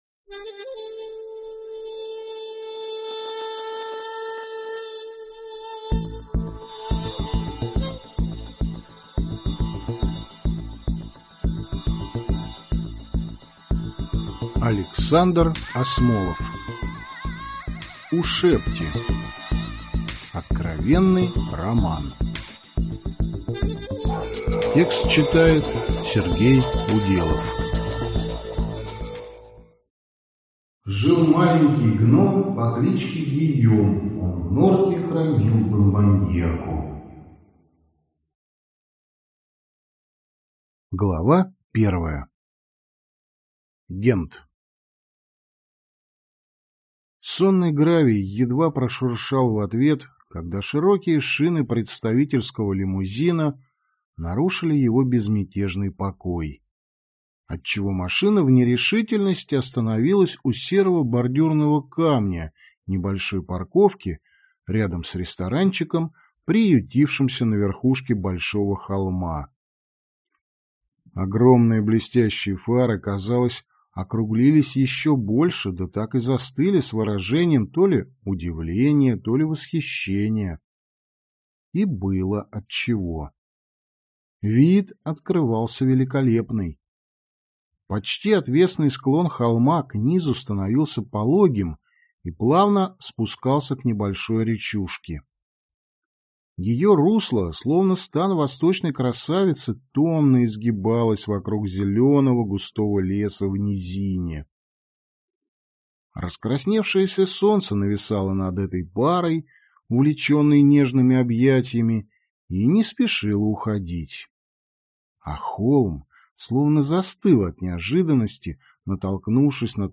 Аудиокнига Ушебти | Библиотека аудиокниг